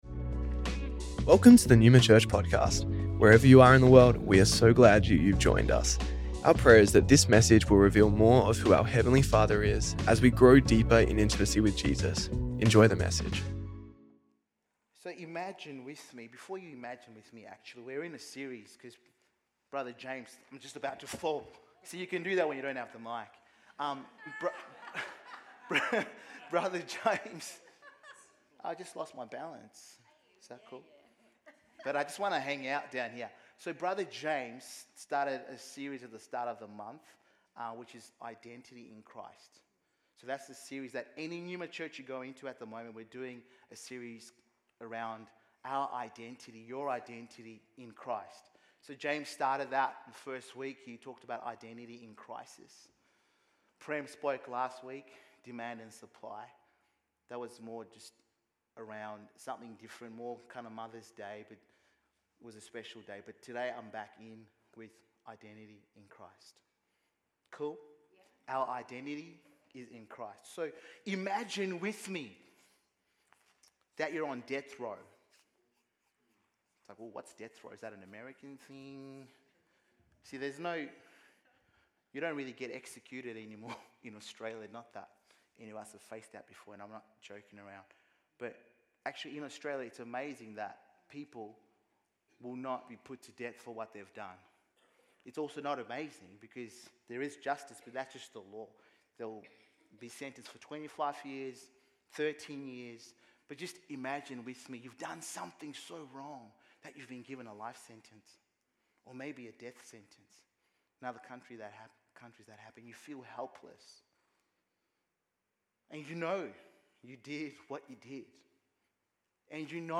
Neuma Church Melbourne South Originally recorded at the 10AM Service on Sunday 18th May 2025